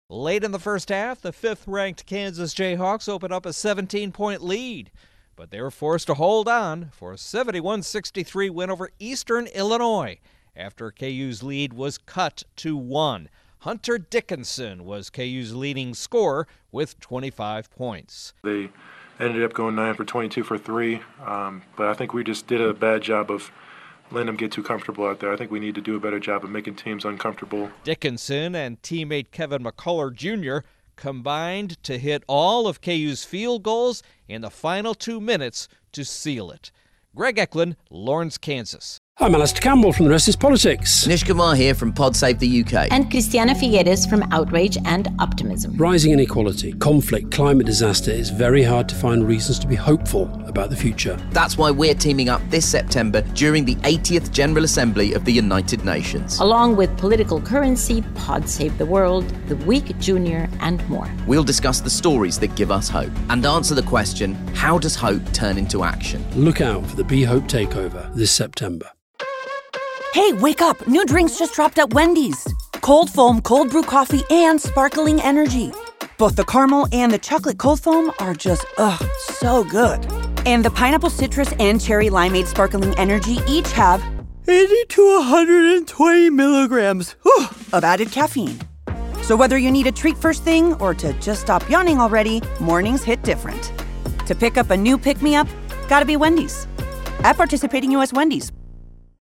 Latest Stories from The Associated Press